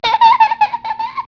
kidlaf08.wav